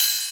VEC3 Ride
VEC3 Cymbals Ride 09.wav